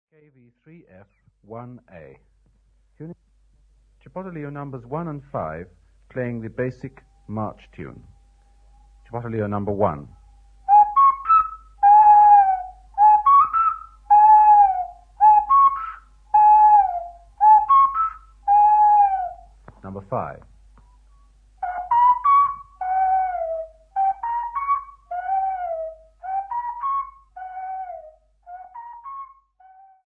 JBFT17-KV9-KV3F1A.mp3 of Marching song